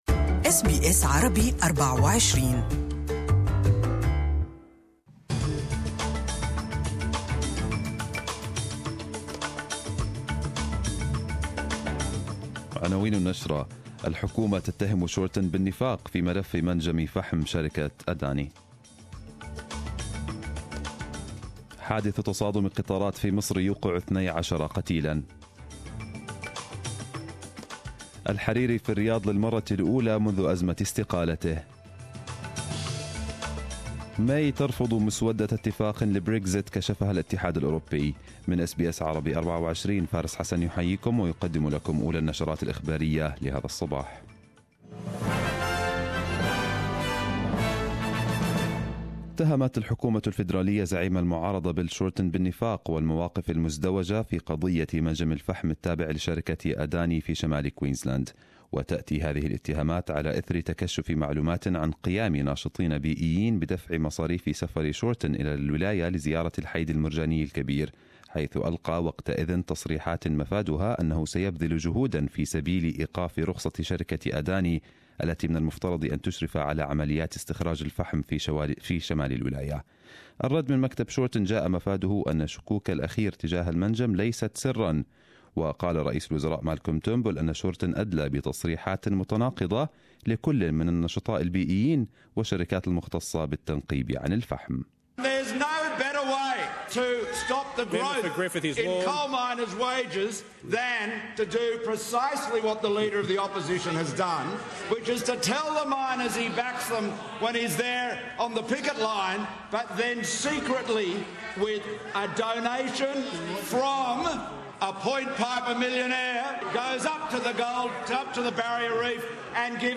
Arabic News Bulletin 01/03/2018